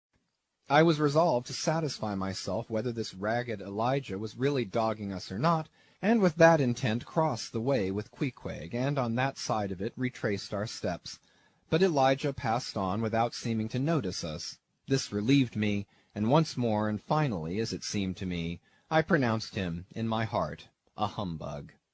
英语听书《白鲸记》第317期 听力文件下载—在线英语听力室